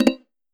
player-joined.wav